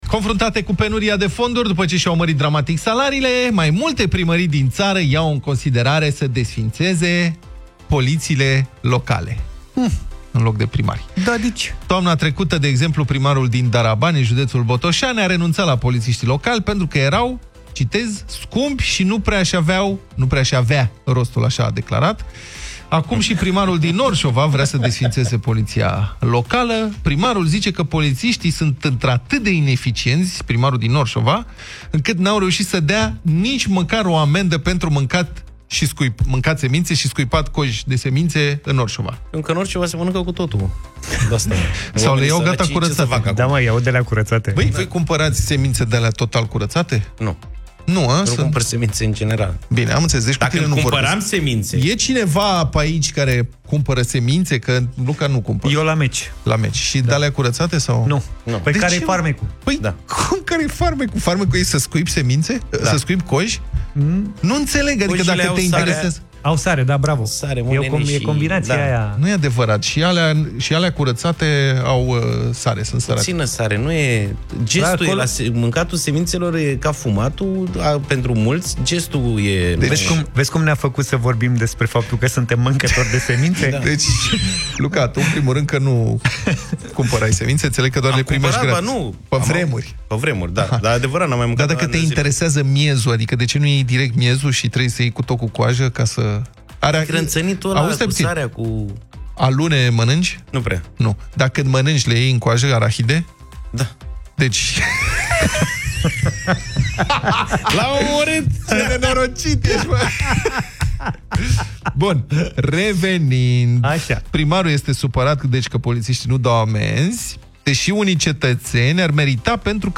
Puteți reasculta Deșteptarea din secțiunea podcast Europa FM, cu ajutorul aplicației gratuite pentru Android și IOS Europa FM sau direct în Spotify și iTunes.